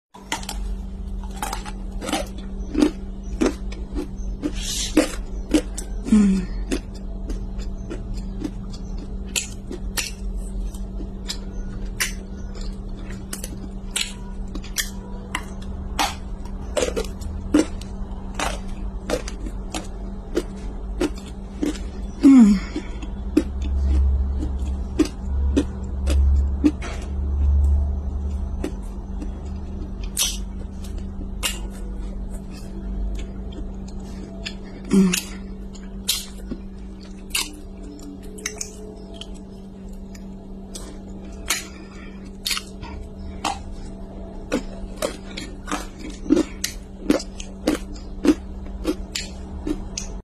dégustation kaolin xxl. relax sound sound effects free download
edible clay. cookies time. best crunchy.